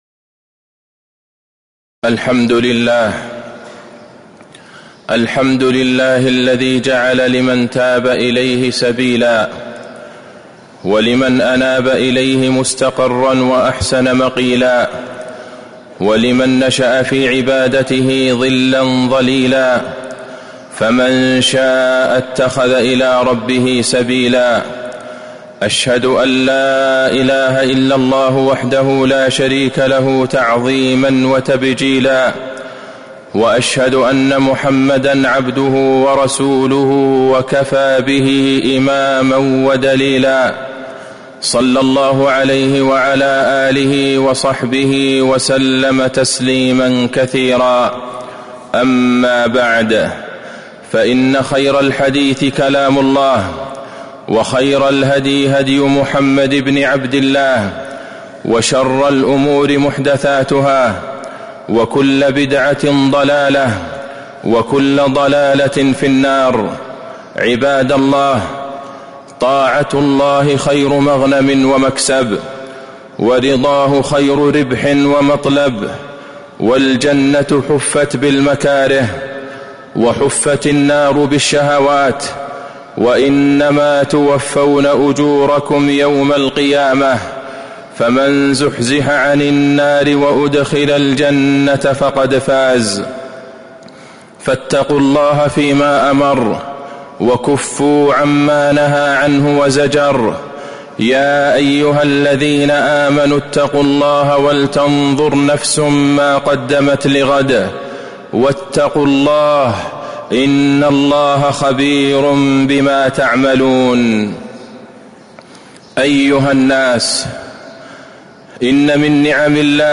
تاريخ النشر ٢٩ شعبان ١٤٤٦ هـ المكان: المسجد النبوي الشيخ: فضيلة الشيخ د. عبدالله بن عبدالرحمن البعيجان فضيلة الشيخ د. عبدالله بن عبدالرحمن البعيجان وصايا في استقبال رمضان